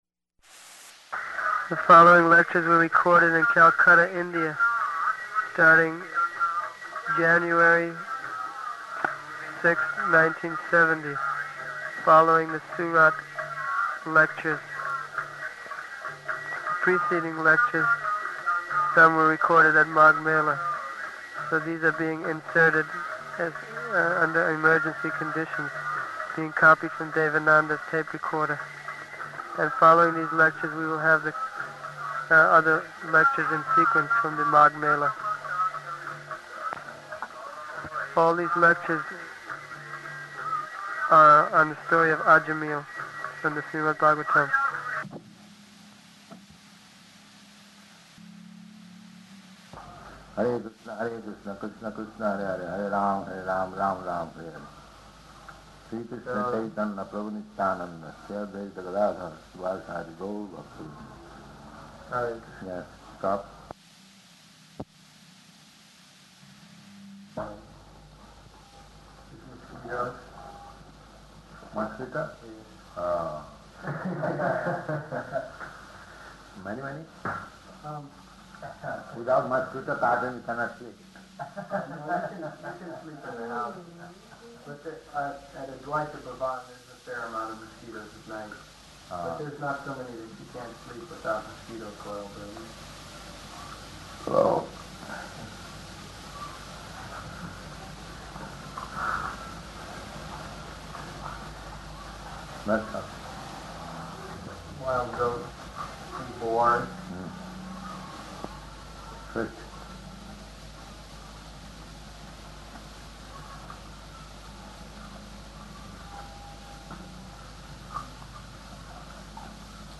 Room Conversation [partially recorded]
Location: Calcutta